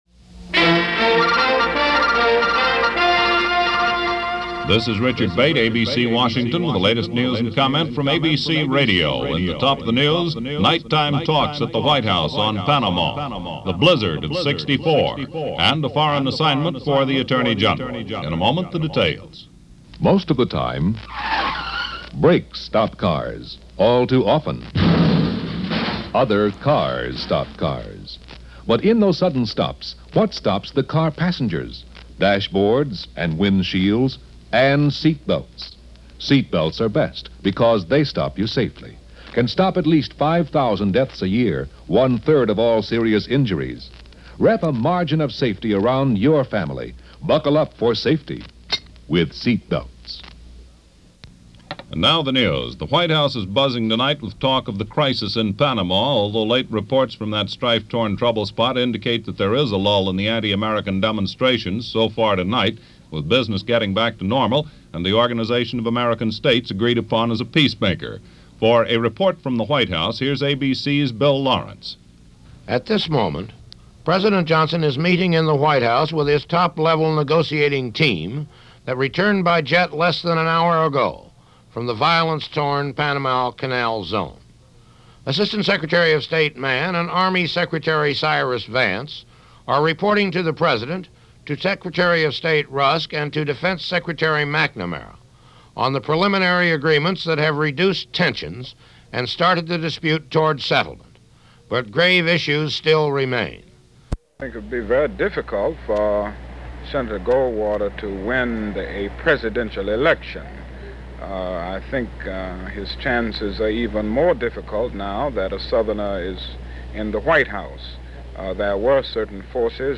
All that, and an abrupt Network cut to a comment from Dr. Martin Luther King on Barry Goldwater and news regarding the prospects for who would be running against LBJ in the upcoming 1964 Presidential election as well as news regarding Cyprus and the ever-present Berlin.
And that’s a small slice of the news for this January 21, 1964 as reported by ABC Radio News on the Hour.